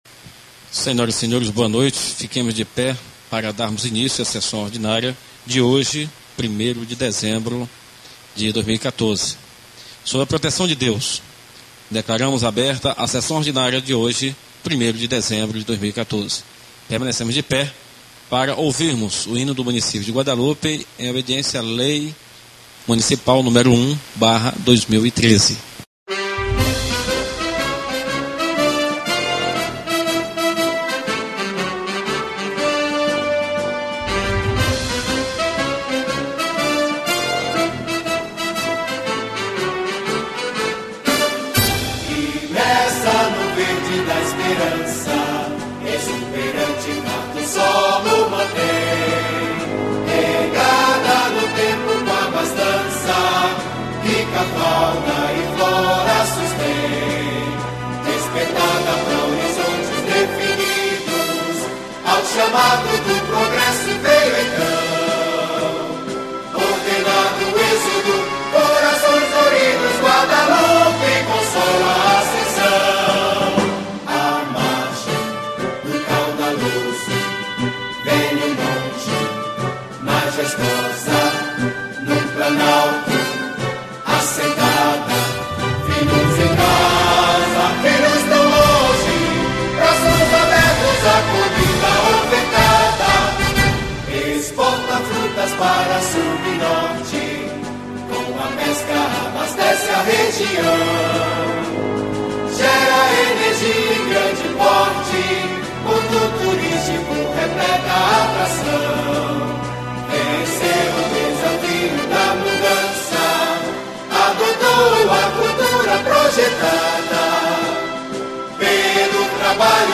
Áudio das Sessões do ano de 2014